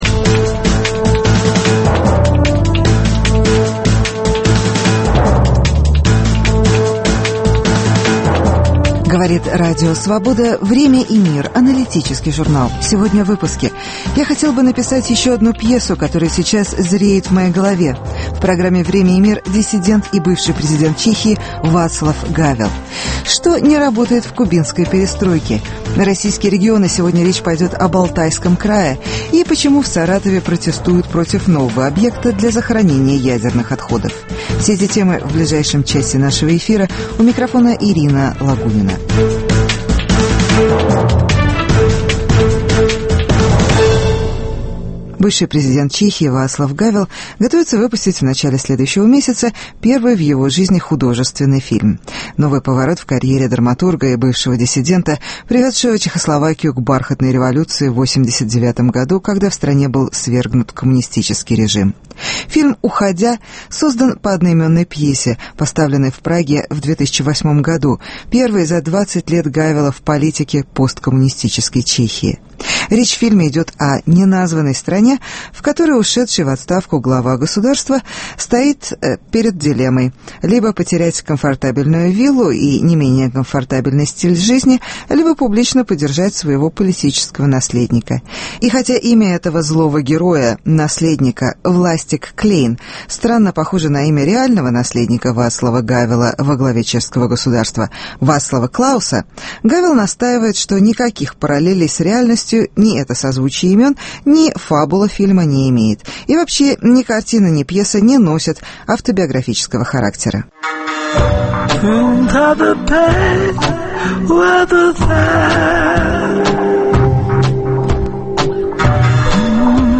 Интервью с Вацлавом Гавелом